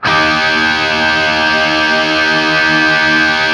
TRIAD D# L-R.wav